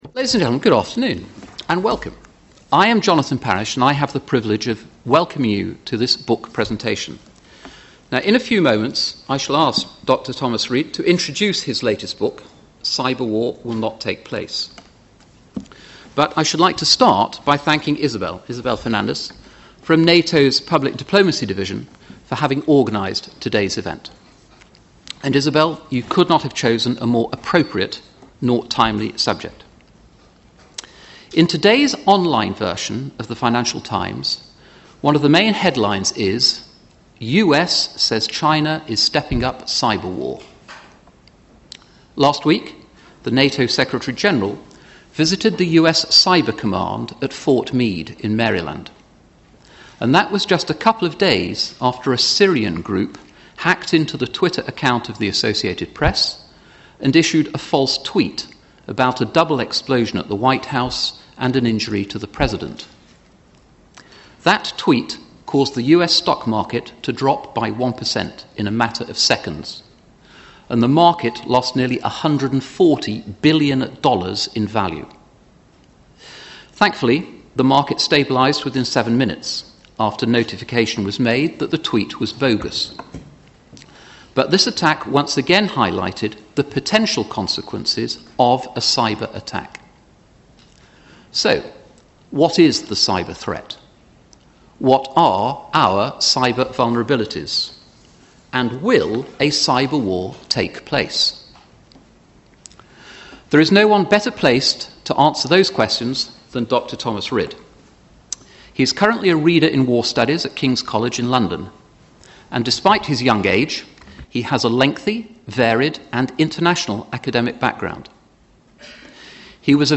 Author Dr Thomas Rid presented his book Cyber War Will Not Take Place and took part in a discussion organised by the NATO Multimedia Library on 7 May 2013.